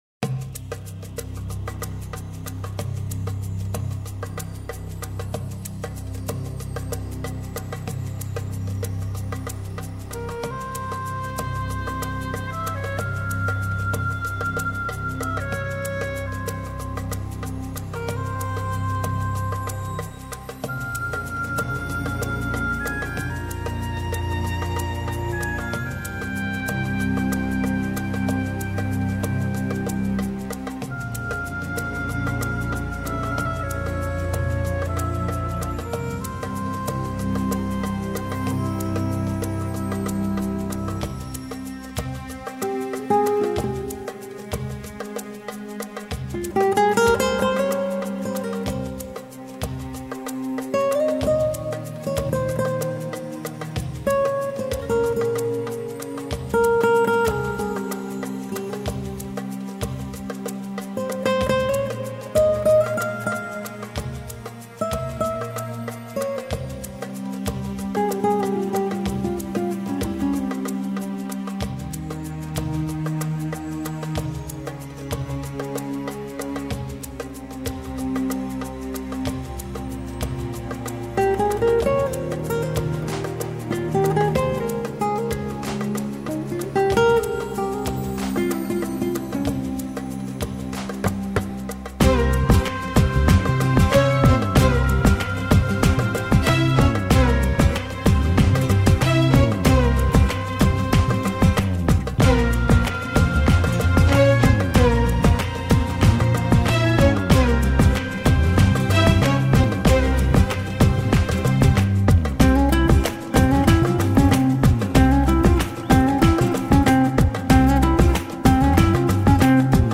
[زبان موسیقی : لایت ( بدون کلام ) ]